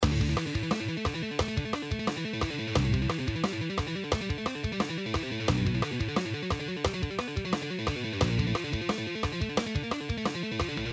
Here if you want to listen only the monster lick part :
Drop D Tuning